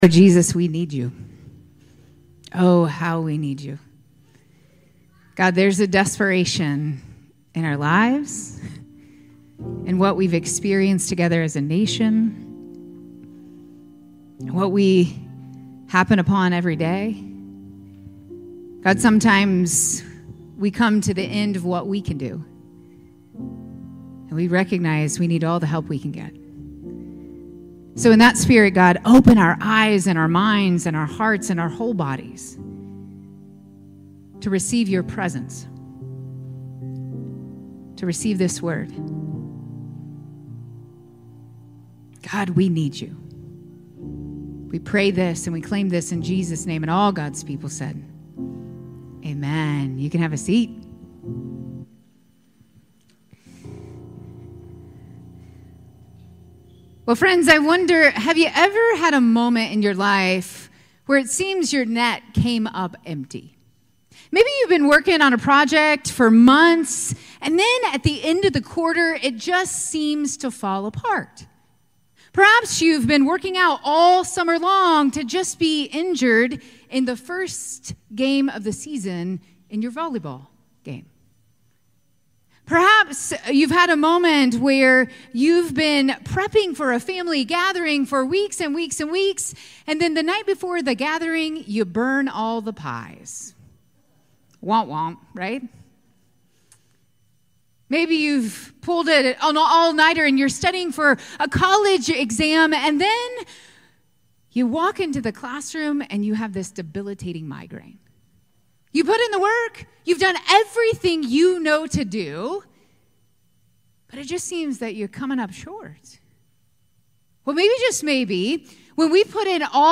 July 14, 2024 Sermon